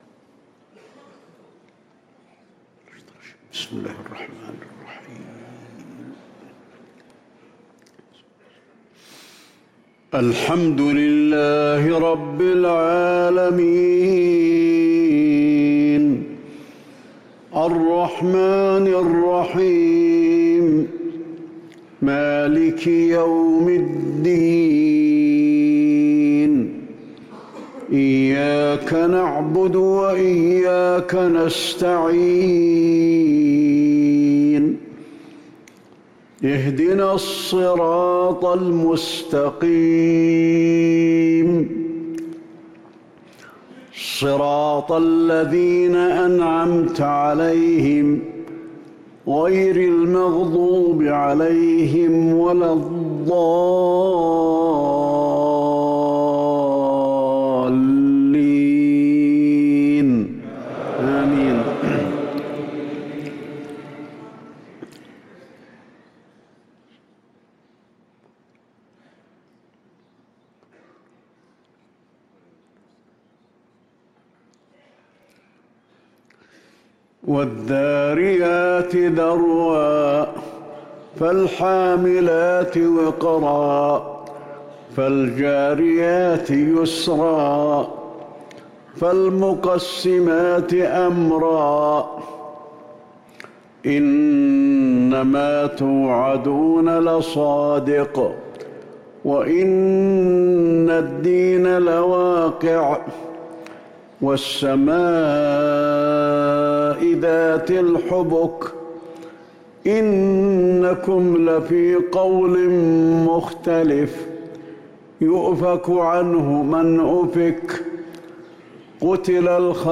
صلاة المغرب للقارئ علي الحذيفي 21 رمضان 1444 هـ
تِلَاوَات الْحَرَمَيْن .